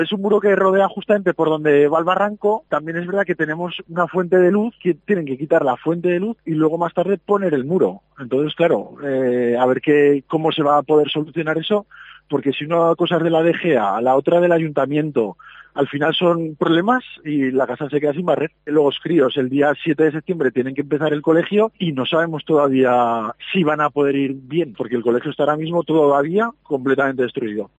Uno de los padres nos habla de la construcción del muro y de cómo se encuentra el colegio